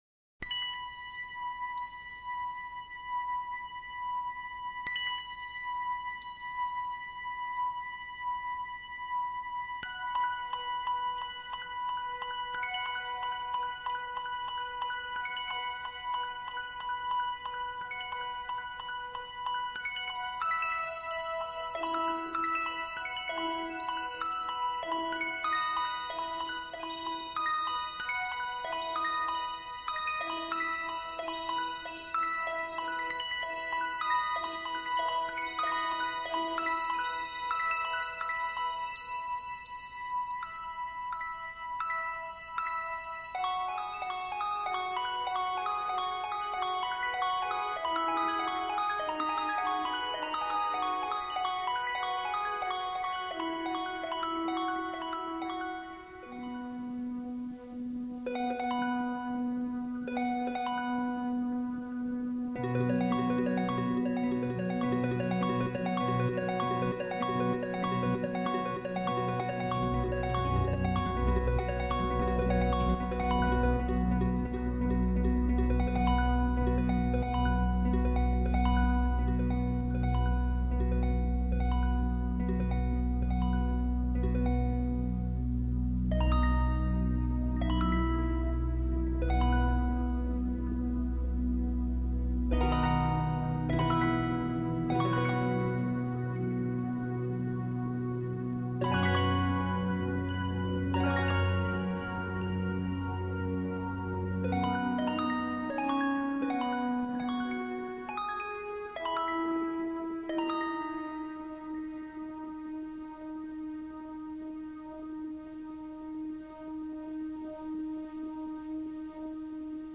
a meditation music CD...
all music recorded live in-studio